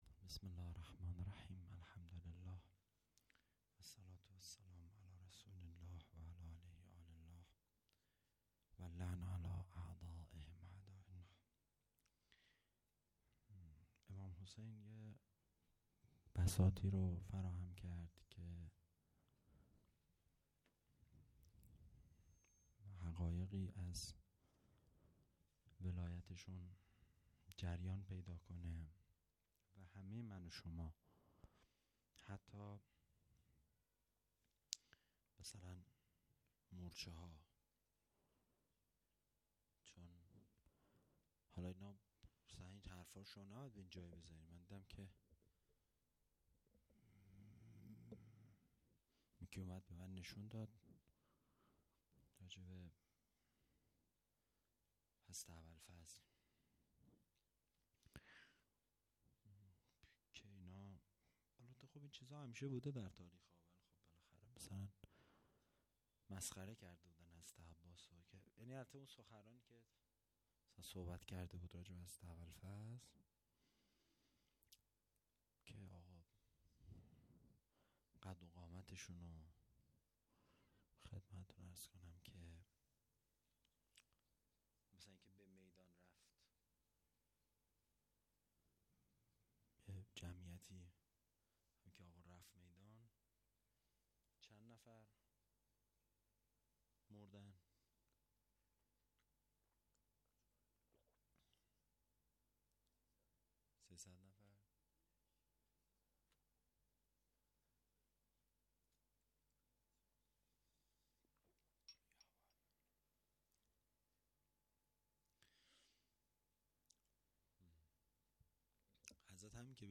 سخنرانی
شب سوم محرم الحرام ۱۴۴۳